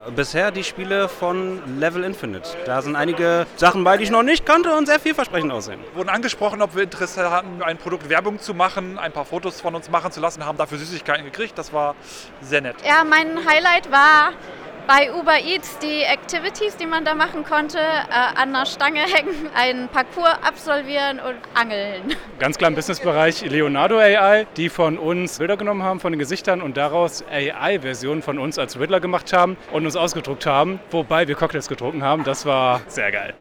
Umfrage  Ressort